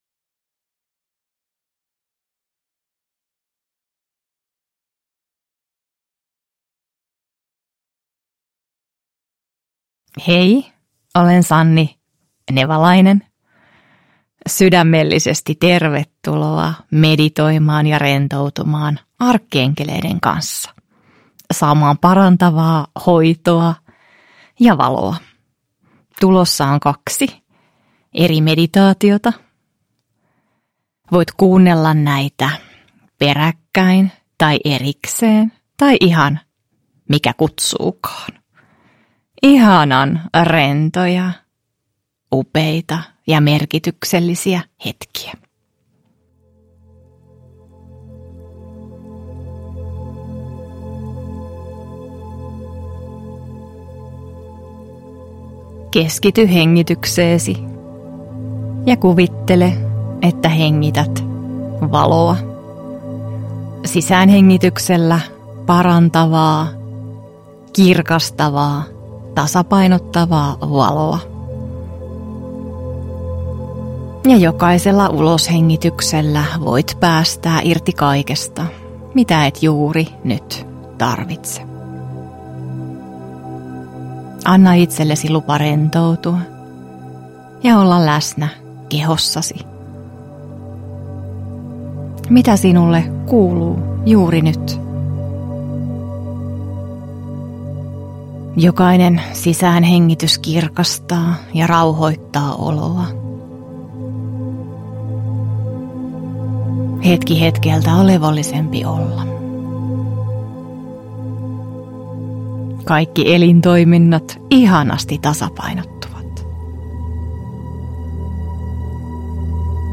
Arkkienkelimeditaatiot – Ljudbok